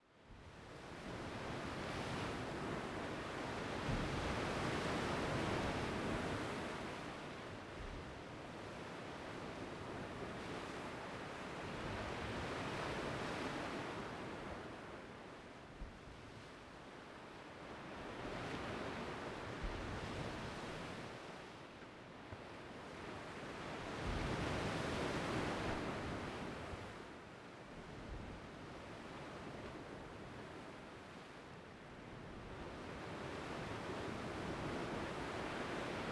WaterWaves2.wav